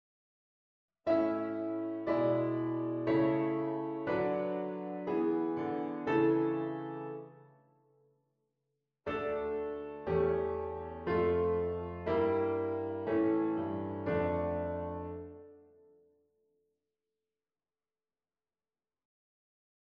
V4/3 - I6 met dalend septime